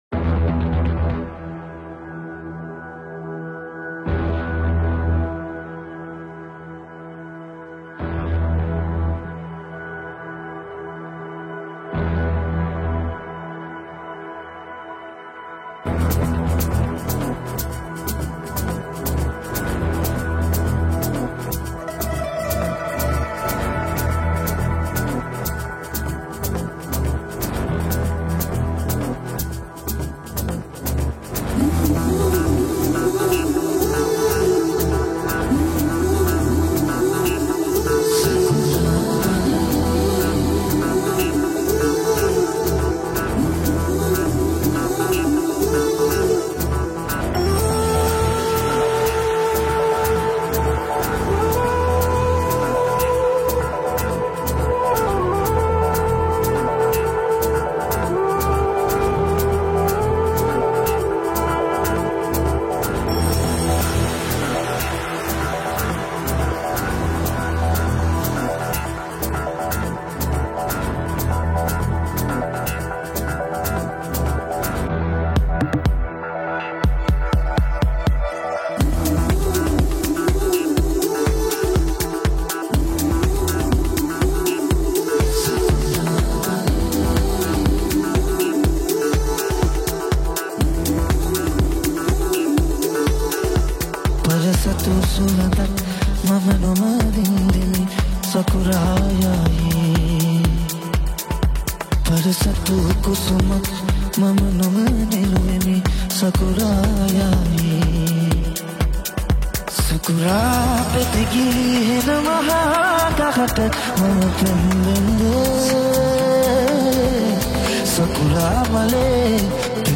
Releted Files Of Sinhala New Dj Remix Single Mp3 Songs